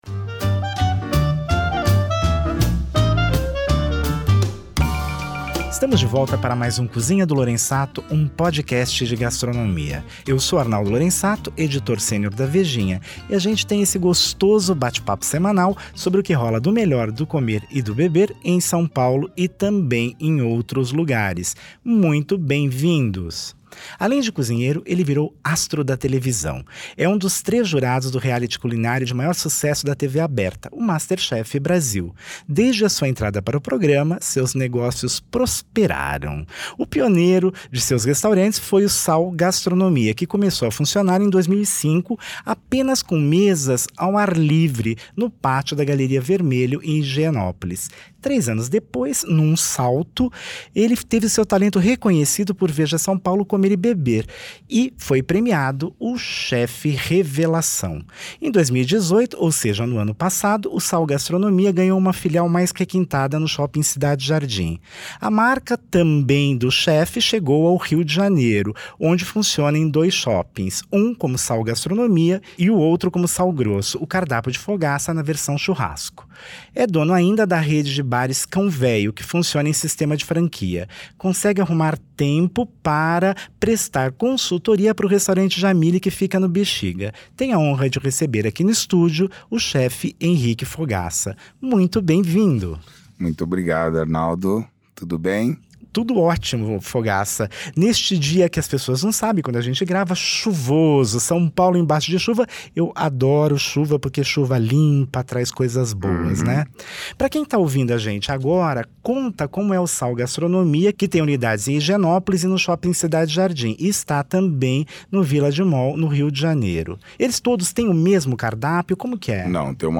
O convidado Henrique Fogaça, reconhecido até fora do Brasil por sua participação no reality culinário MasterChef Brasil, responde a todas as perguntas de nossa agradável conversa sem nunca perder o bom humor. E olha que gravamos esse papo num dia que o trânsito amarrou em São Paulo por causa da chuva torrencial e tivemos um probleminha técnico no estúdio.